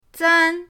zan1.mp3